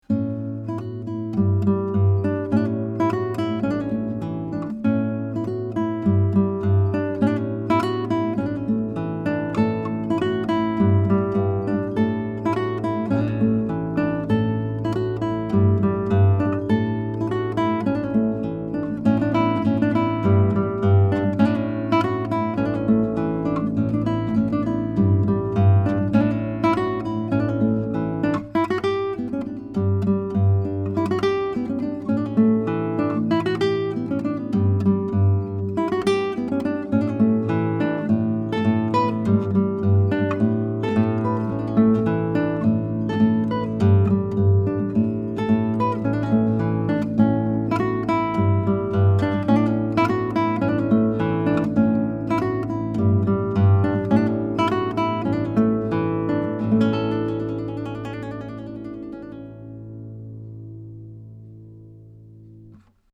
Here are some of my sound files of the BB4 equipped with an Audient Black Preamp -- just to show you the sound of the Black Series preamp, using a Blackspade Acoustics UM17 tube mic, and recorded into a Sony PCM D1 flash recorder, with no EQ or Compression:
SAKURAI HARP GUITAR